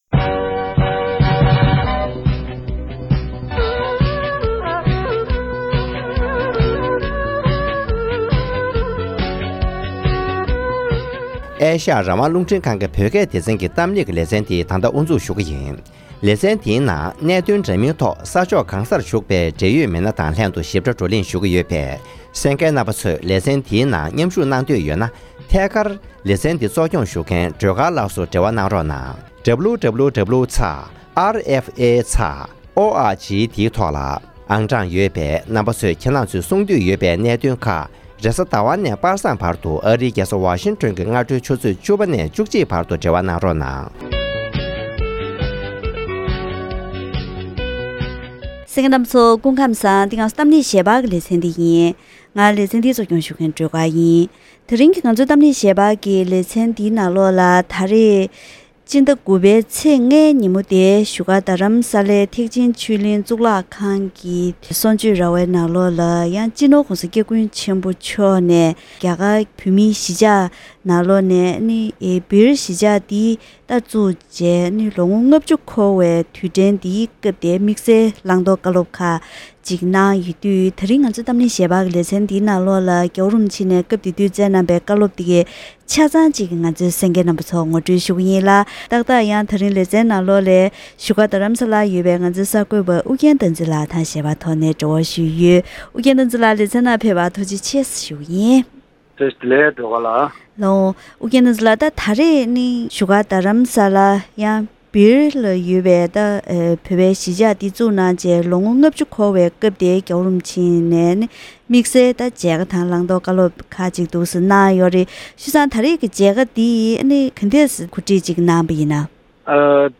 སྦིར་བོད་ཚོགས་གསར་བཙུགས་བྱས་ནས་ལོ་ངོ་ལྔ་བཅུ་འཁོར་བའི་སྐབས་༸གོང་ས་མཆོག་ནས་བཀའ་སློབ་སྩལ་བ།